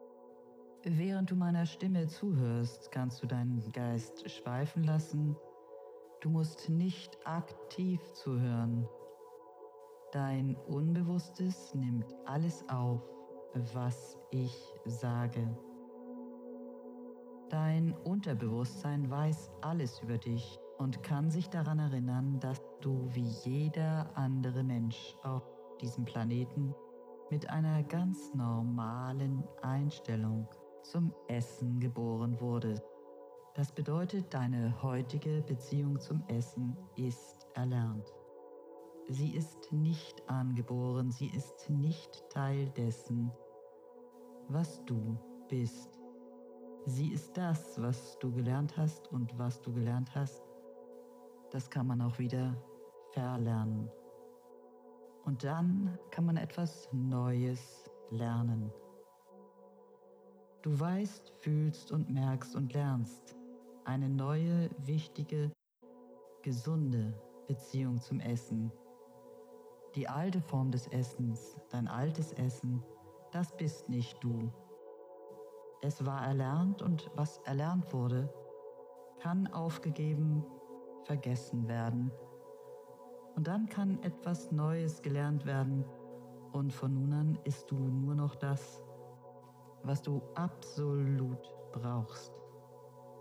Meditation zum Buch: